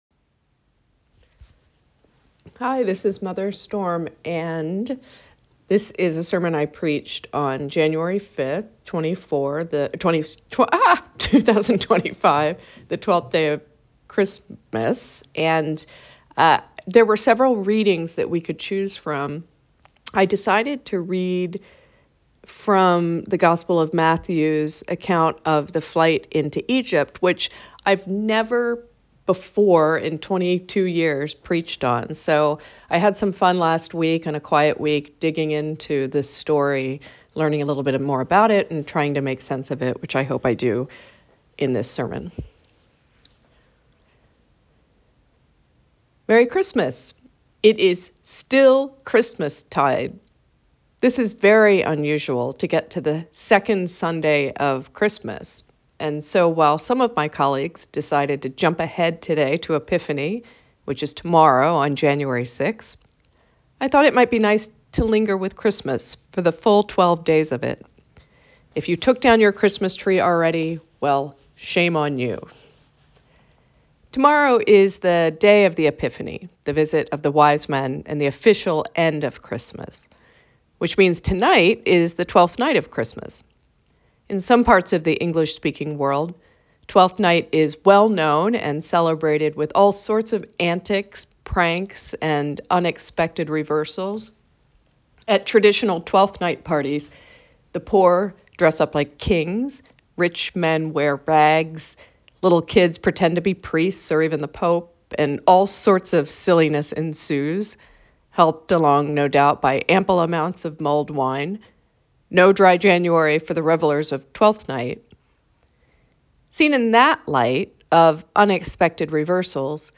Even Egypt! A Sermon for Twelfth Night